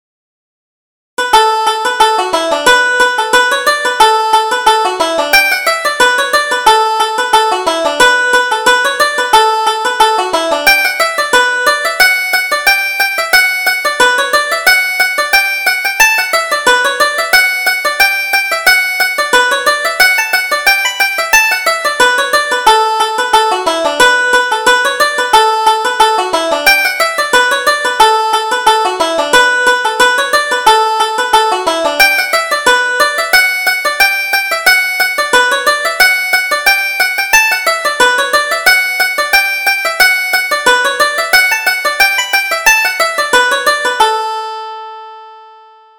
Reel: The Wind that Shakes the Barley